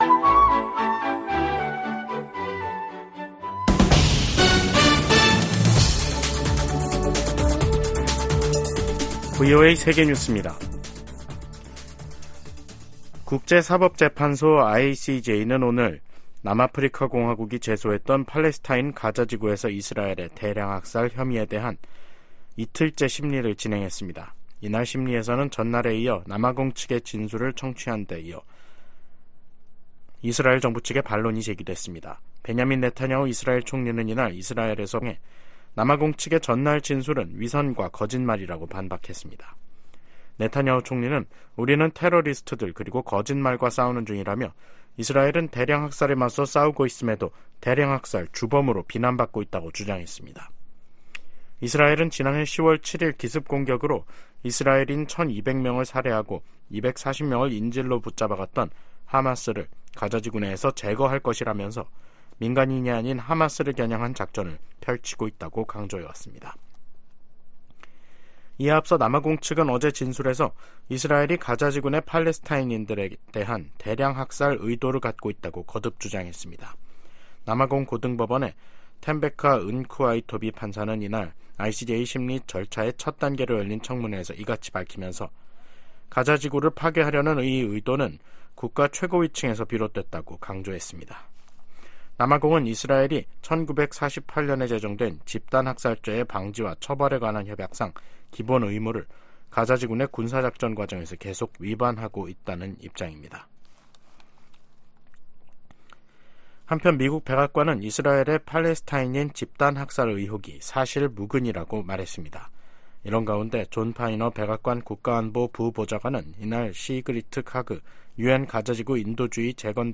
VOA 한국어 간판 뉴스 프로그램 '뉴스 투데이', 2024년 1월 12일 2부 방송입니다. 미국이 북한 탄도미사일의 러시아 이전과 시험에 관여한 러시아 기관과 개인에 제재를 가했습니다. 미 국무부는 북한제 미사일 사용 증거가 없다는 러시아 주장을 일축했습니다. 국제 인권단체 휴먼라이츠워치는 '2024 세계 보고서'에서 북한 정부가 지난해에도 코로나 방역을 핑계로 계속 주민들의 기본권을 침해했다고 비판했습니다.